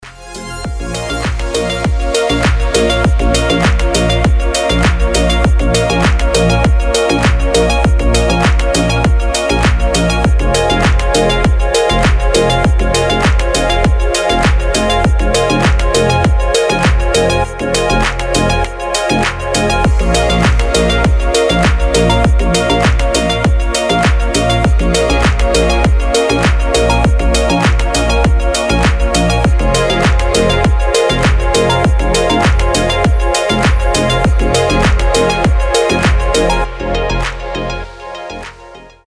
• Качество: 320, Stereo
громкие
deep house
мелодичные
без слов
ремиксы
Красивая танцевальная музыка от турецких диджеев